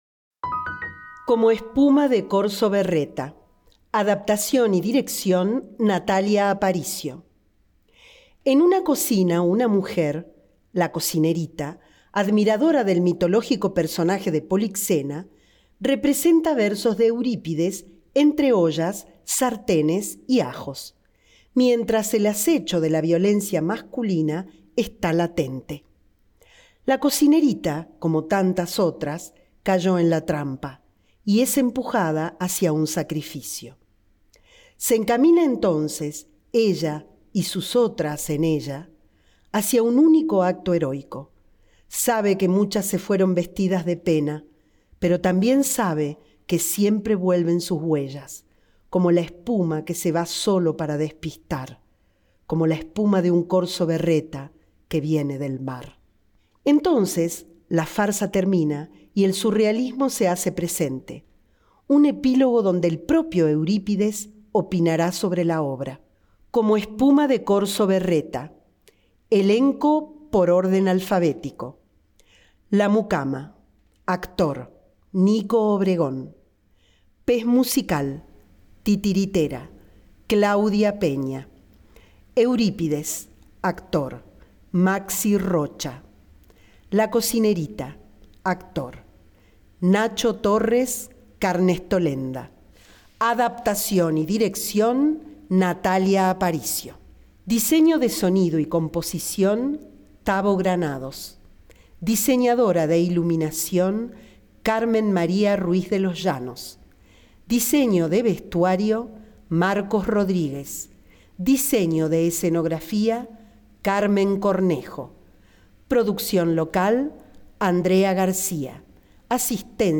TNC Accesible | El siguiente audio corresponde a lectura del programa de mano del espectáculo Como espuma de Corso Berreta